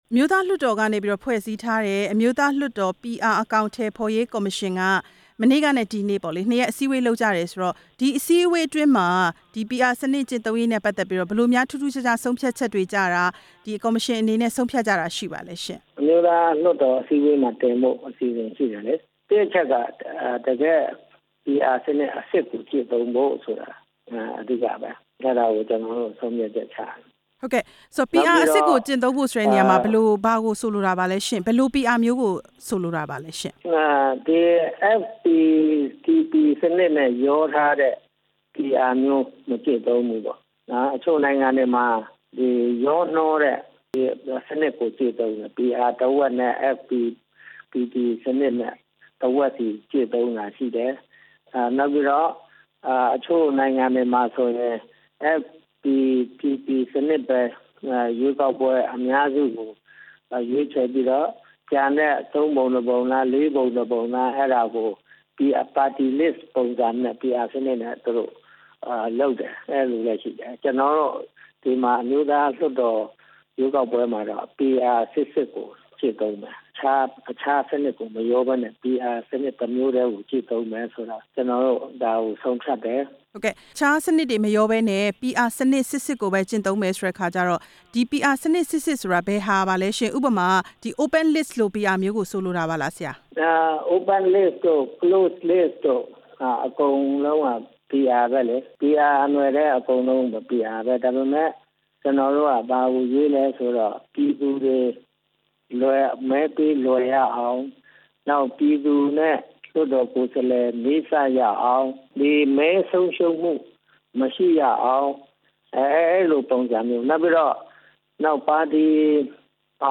ဒေါက်တာ ဗညားအောင်မိုးနဲ့ မေးမြန်းချက်